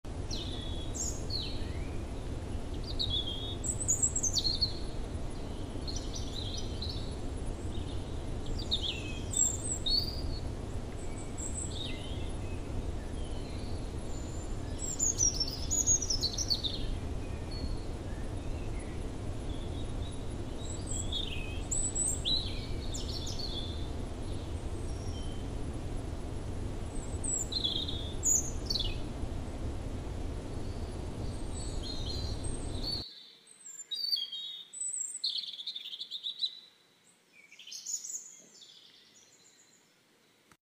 Som de manhã ensolarada para sound effects free download
Som de manhã ensolarada para estudar e focar.